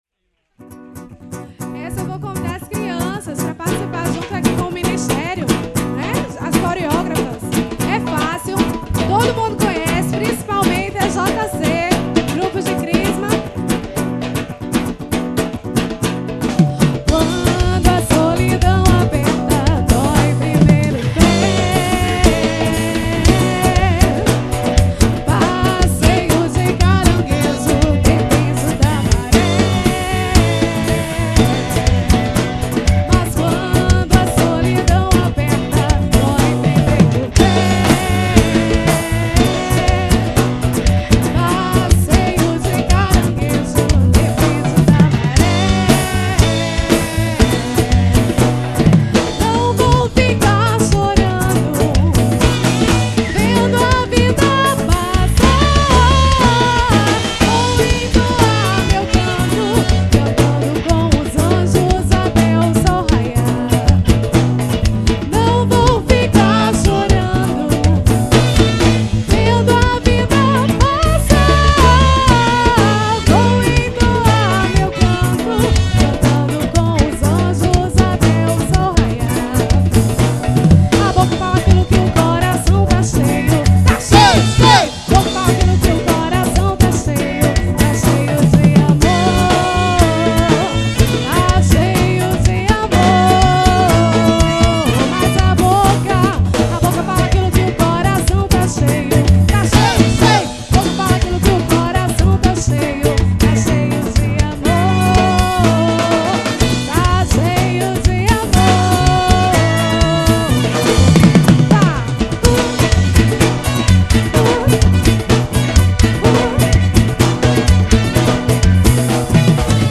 Composição: Católica.